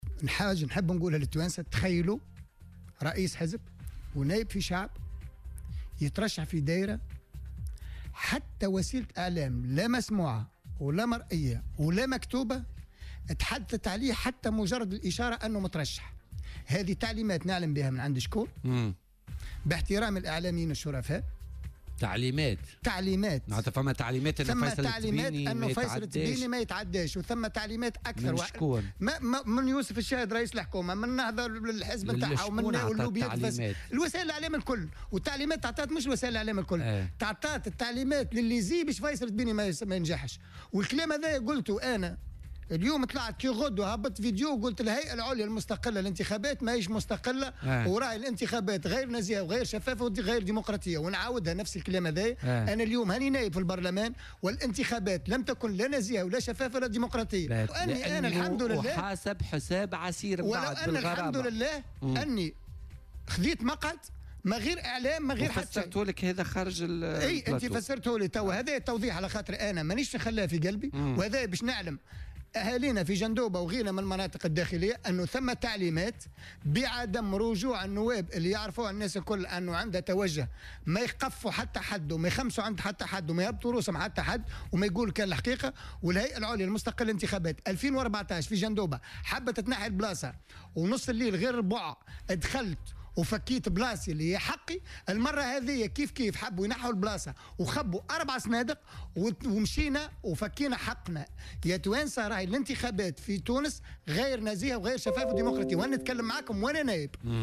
وأضاف في مداخلة له اليوم في برنامج "بوليتيكا" على "الجوهرة أف أم" أنه على الرغم من ذلك تمكّن من الحصول على مقعد في البرلمان الجديد، متّهما الهيئة العليا المستقلة للانتخابات بالسعي إلى انتزاع هذا المقعد.